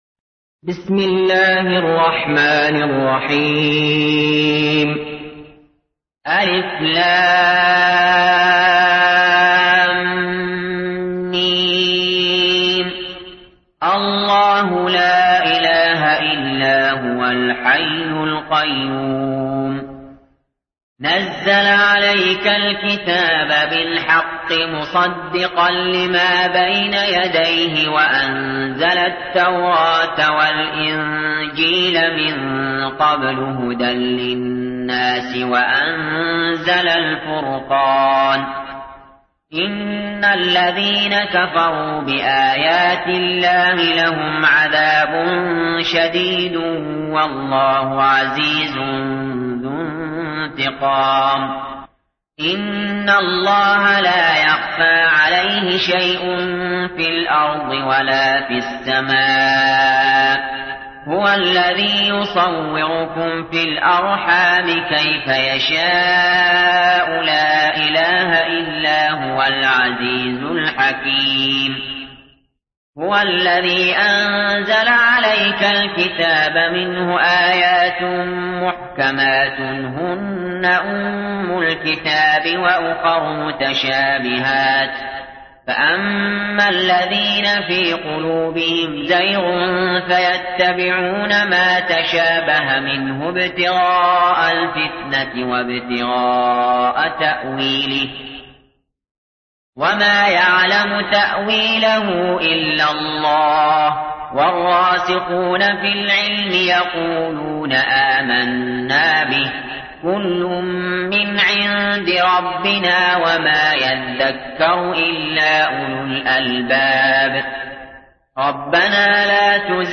تحميل : 3. سورة آل عمران / القارئ علي جابر / القرآن الكريم / موقع يا حسين